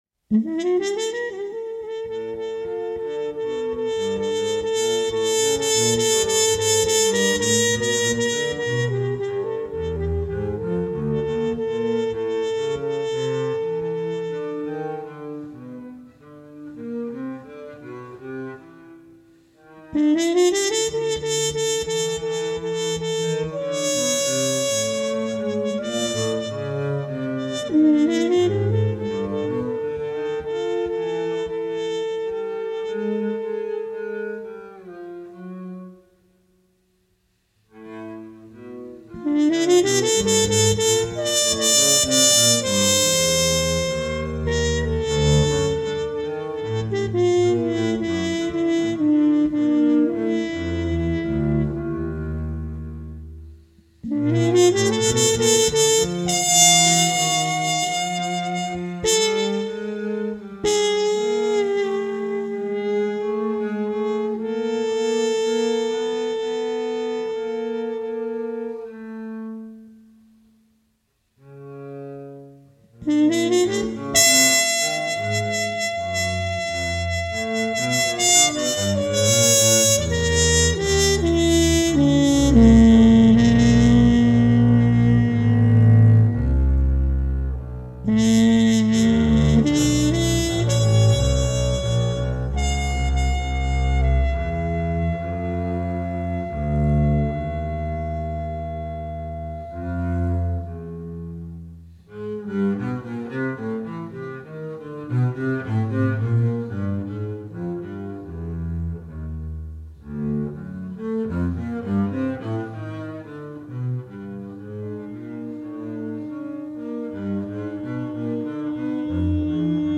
trumpet
double bass